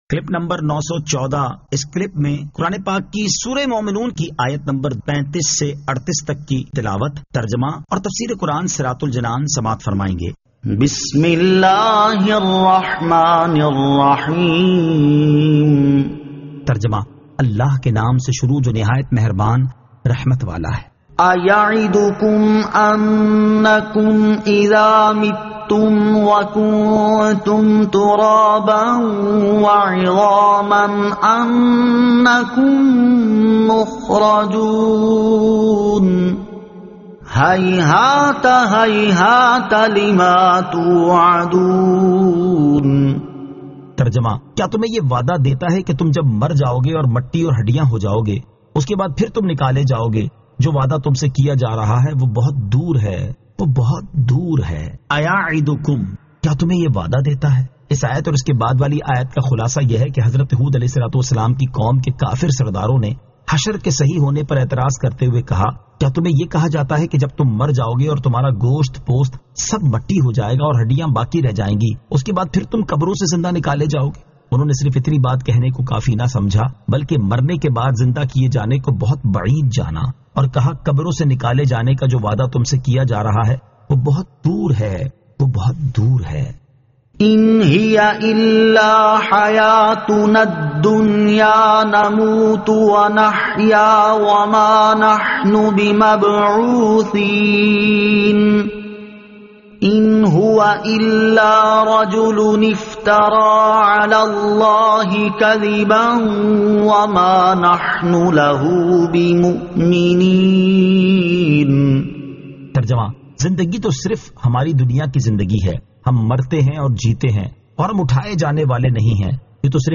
Surah Al-Mu'minun 35 To 38 Tilawat , Tarjama , Tafseer